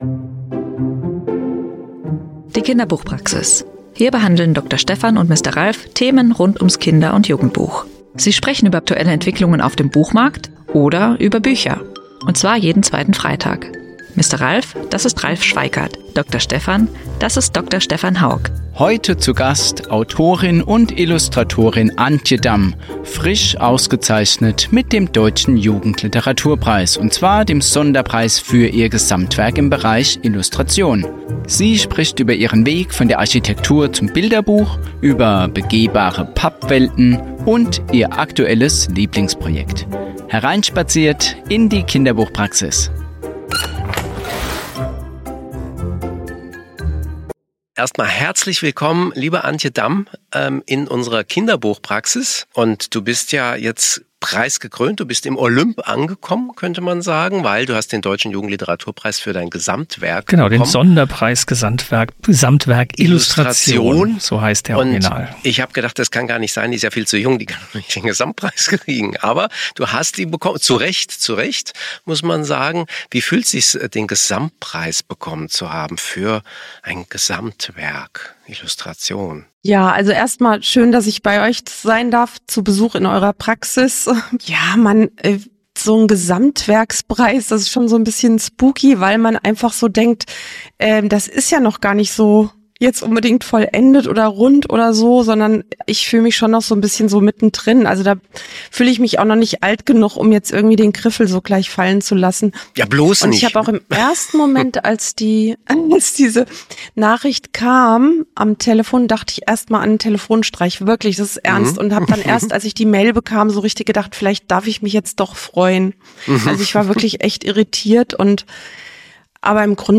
Antje Damm, Trägerin des Deutschen Jugendliteraturpreises, spricht über ihre Arbeit zwischen Architektur, Illustration und erzählerischem Experiment. Ein Gespräch über Räume aus Pappe, Kinder als ernstzunehmende Gesprächspartner:innen – und die Kunst, Geschichten offen zu lassen.